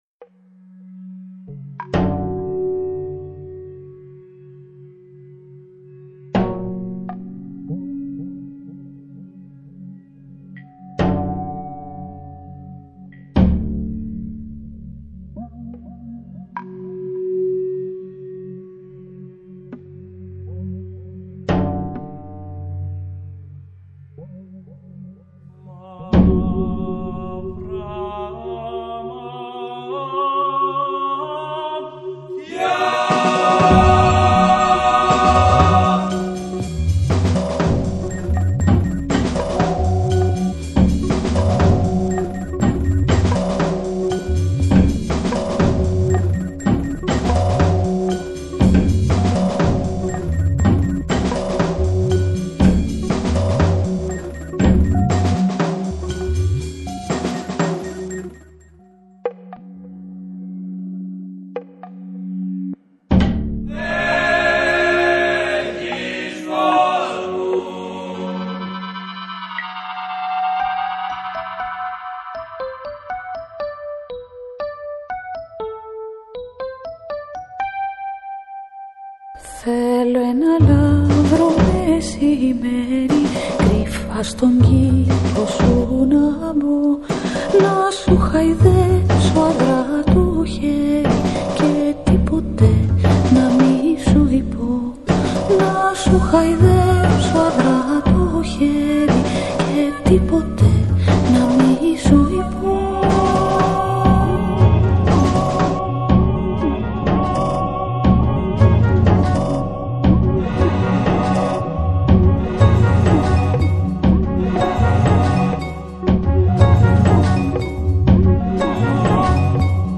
Ηλ. κιθάρα, μαντολίνο, λούπες, samplers, sound editing.